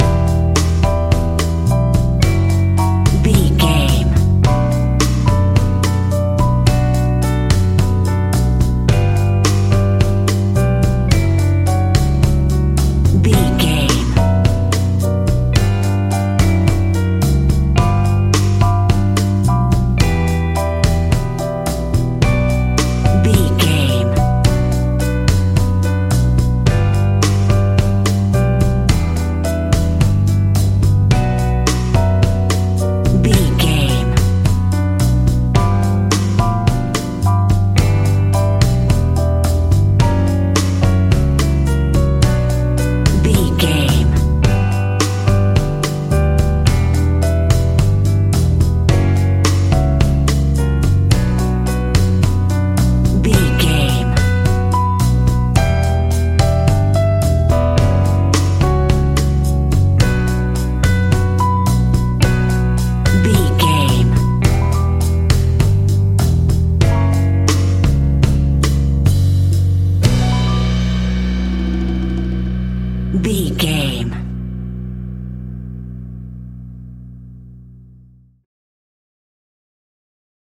An exotic and colorful piece of Espanic and Latin music.
Aeolian/Minor
DOES THIS CLIP CONTAINS LYRICS OR HUMAN VOICE?
maracas
percussion spanish guitar